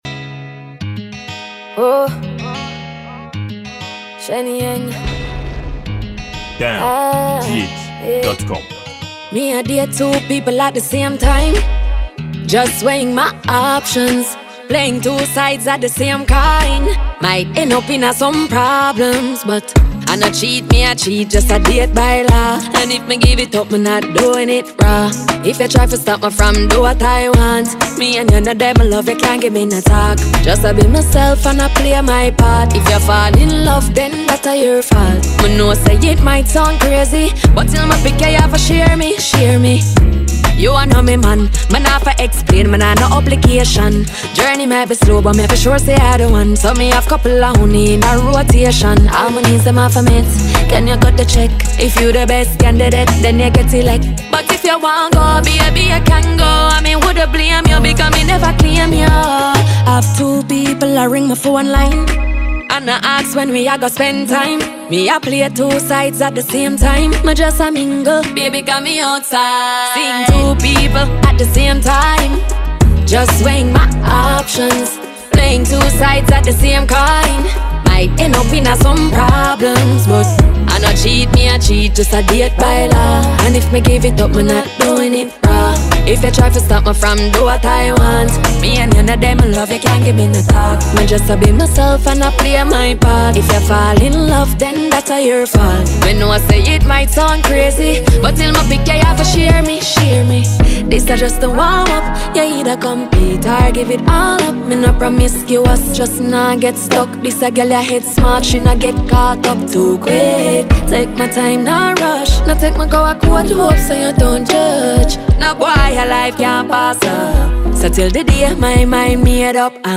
Award winning dancehall musician